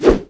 WAV · 26 KB · 單聲道 (1ch)